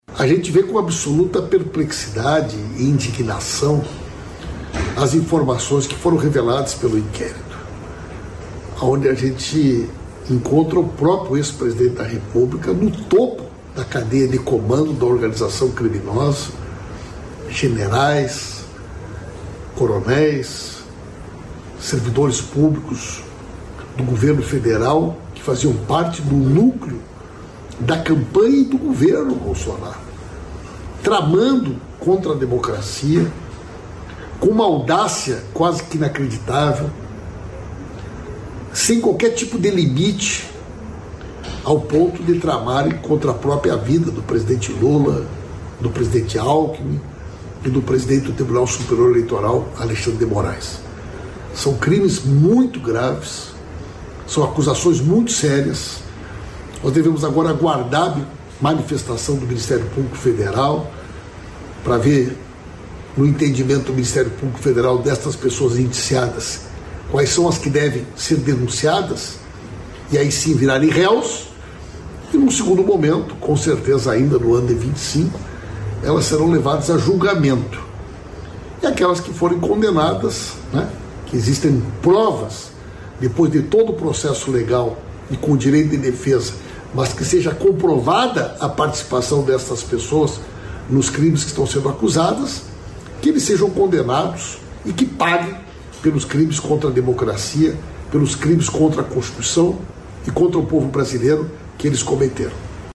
Íntegra do discurso dos ministros dos Transportes, Renan Filho, e da Casa Civil, Rui Costa, na divulgação do Programa de Otimização de Contratos de Concessão Rodoviária, no Palácio do Planalto, em Brasília, nesta quinta-feira (21).